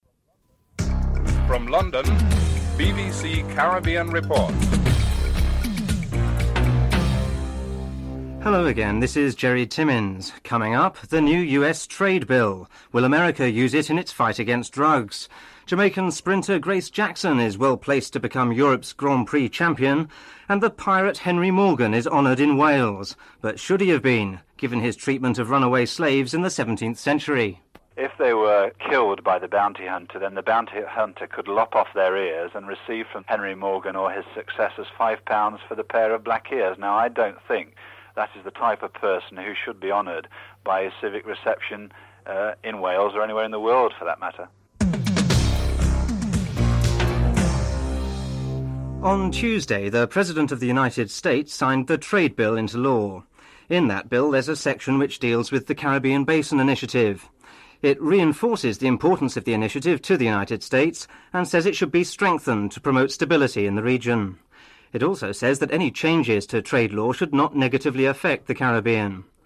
The British Broadcasting Corporation
1. Headlines: (00:00-00:56)
7 Musical interlude with the promotion of the upcoming Notting Hill Carnival celebrations and a special live Carnival edition of the program. (14:38-14:48)